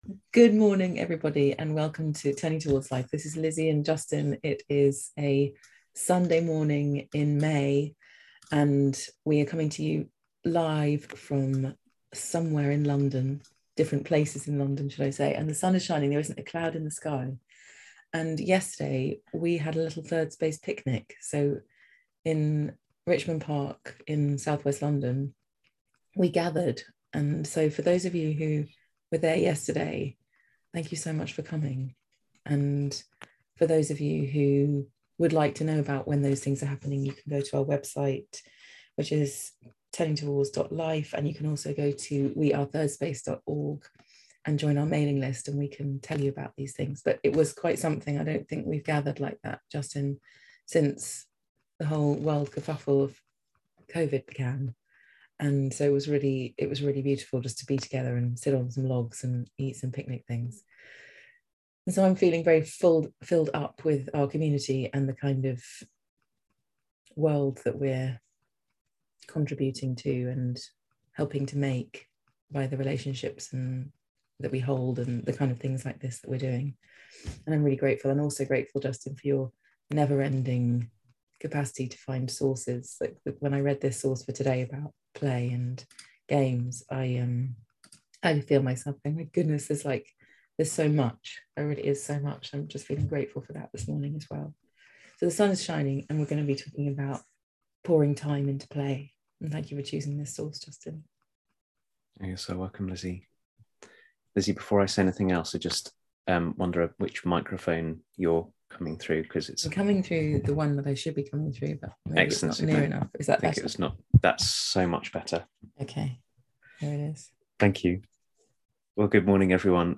This week's Turning Towards Life is a conversation about living into our lives as an infinite unfolding, rather than a finite game.